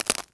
wood_tree_branch_break_02.wav